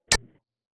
UI_SFX_Pack_61_47.wav